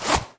描述：我打开/关闭牛仔裤上的苍蝇
标签： 裤子 拉链 拉链
声道立体声